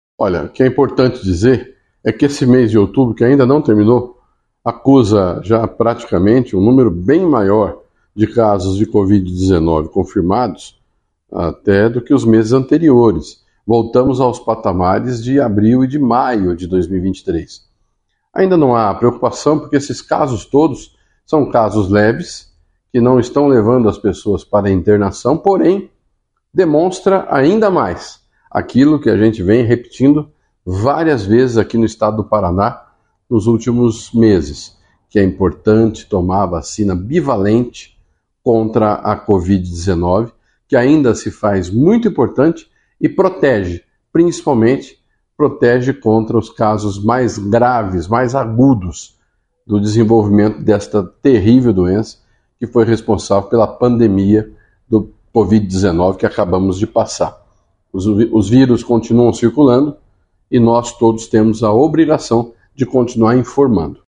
Sonora do secretário da Saúde, Beto Preto, sobre o último boletim semanal da Covid-19